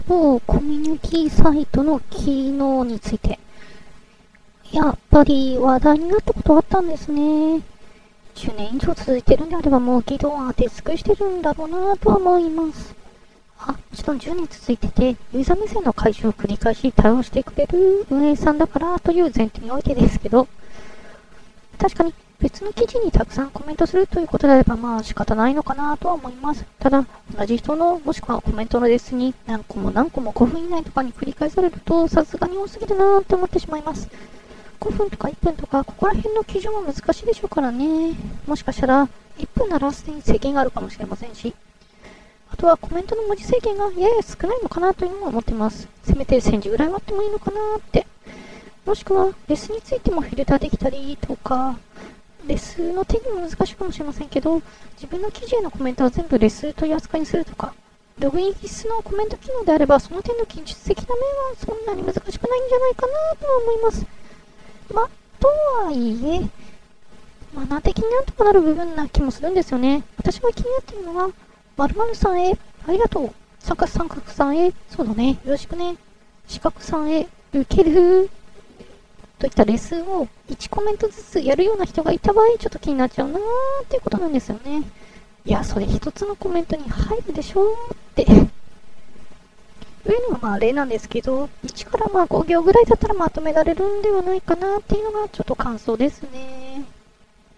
ぐらいにすると、男声から女声に変換できる。
雑音については、「SoundEngine」というフリーソフトで、「ノイズサプレッサー（雑音帯域低減）」というエフェクト？を使用したら少し抑えられた。